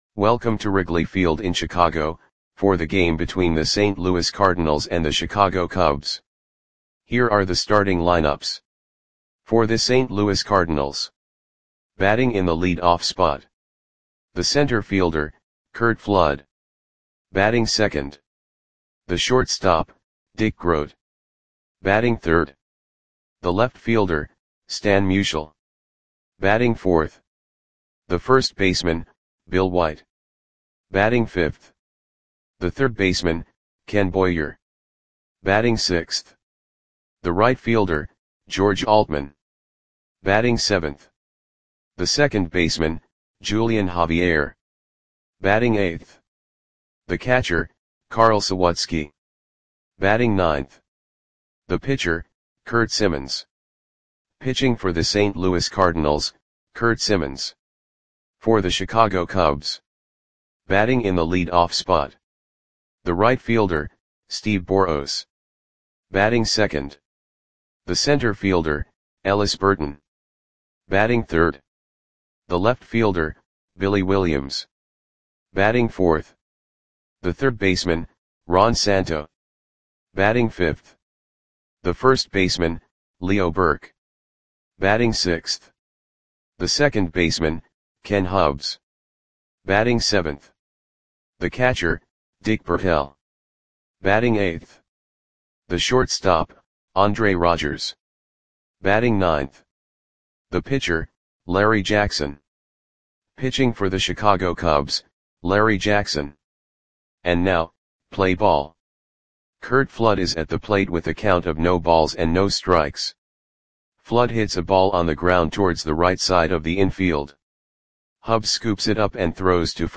Audio Play-by-Play for Chicago Cubs on July 27, 1963
Click the button below to listen to the audio play-by-play.